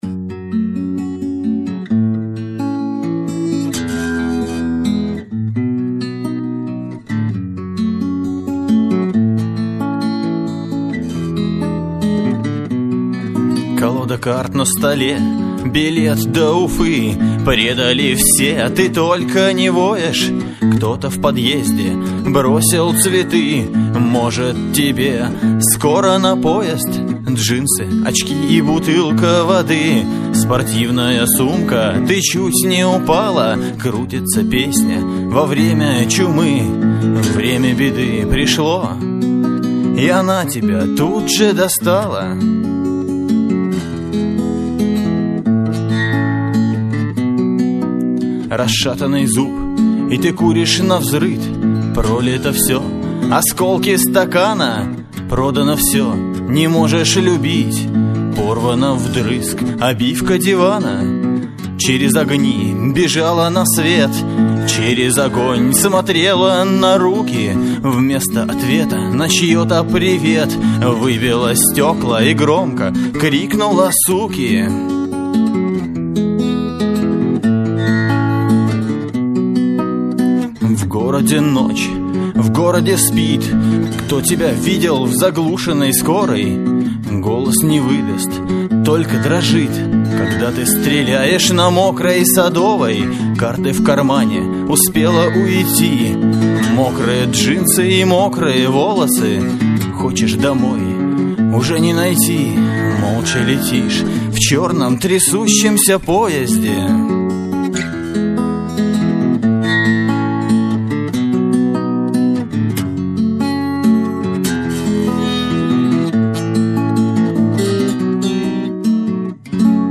112 kbps, stereo, studio sound. audio live